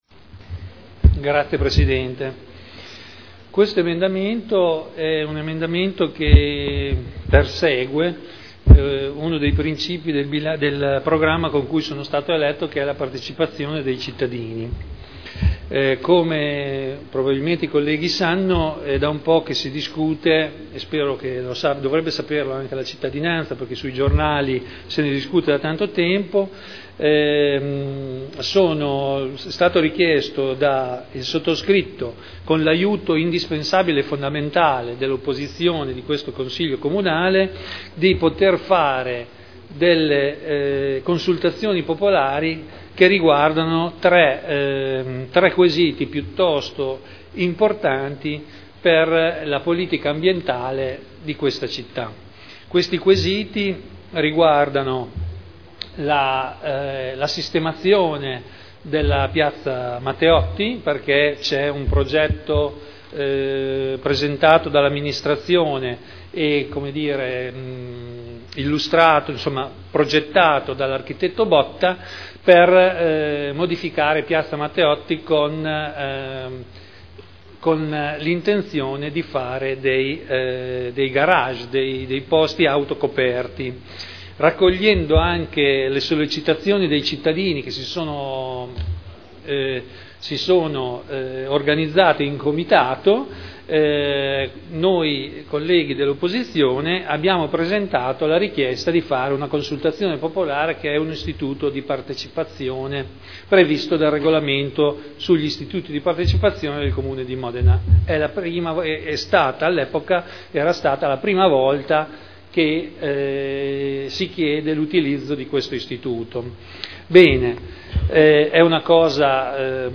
Seduta 28/03/2011. Presentazione di Emendamento n. 29809. Consultazioni popolari per Piazza Matteotti, piscina e inceneritore.